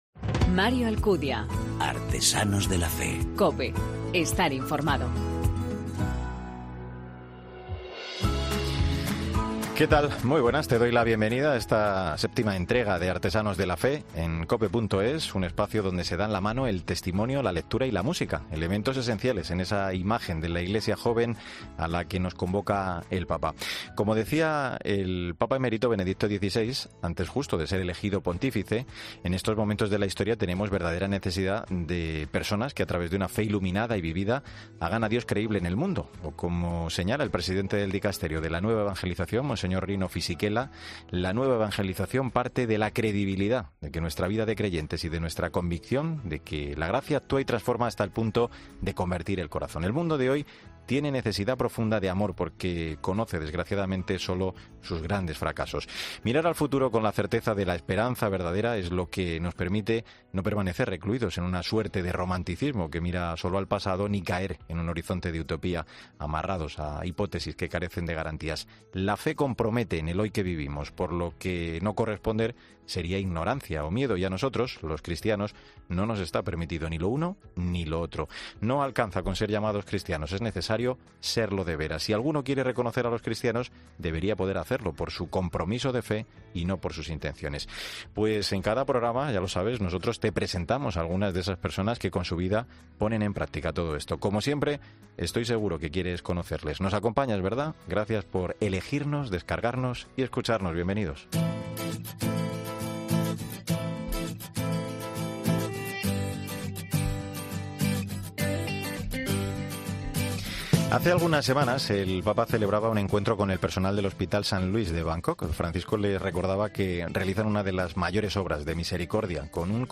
Una interesante entrevista en la que nos cuenta como su prioridad son estos niños enfermos y su pasión es hacer cine. Además dice, que en todo este tiempo ha visto llorar a Dios junto a él y que ha visto muchos milagros.